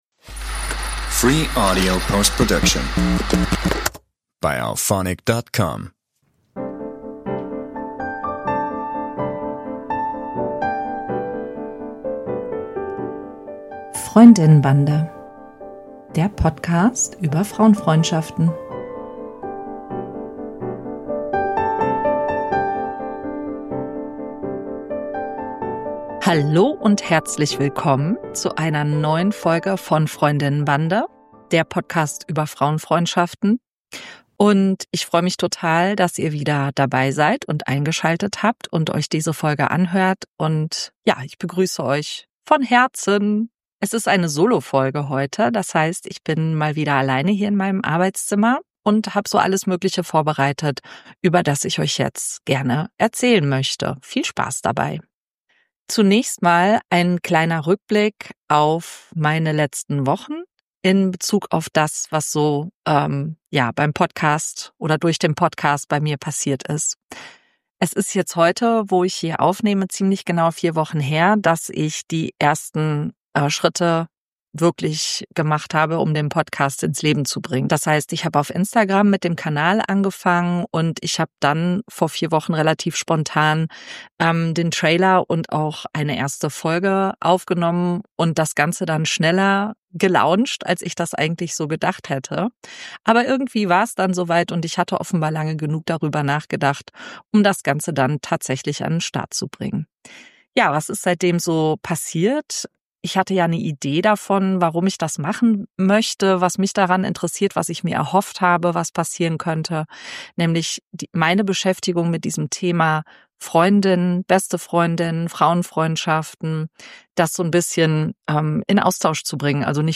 Beschreibung vor 6 Monaten Hier ist Folge 04, ihr hört mich heute mal wieder ganz alleine. Erst mal gibt es einen kleinen Rückblick auf meine ersten Wochen mit dem Podcast und was so passiert ist.